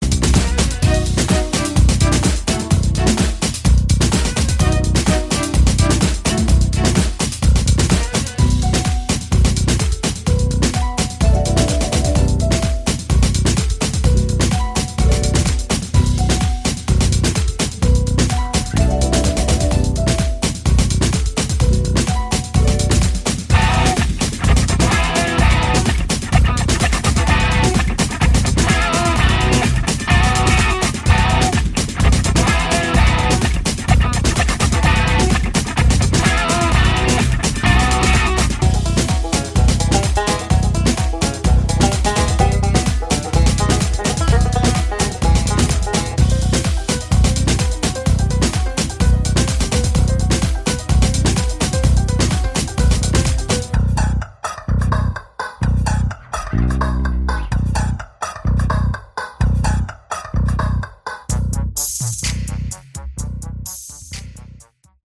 Category: Instrumental Hard Rock